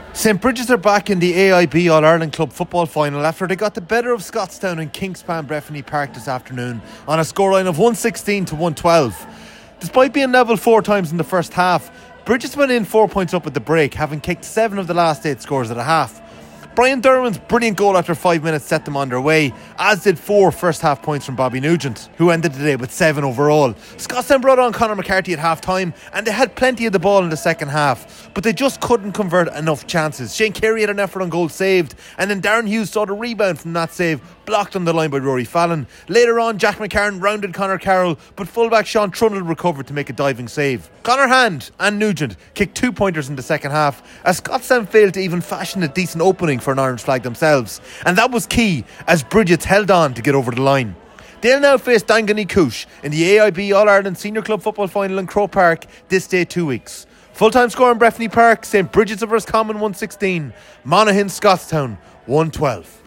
full time report from Cavan…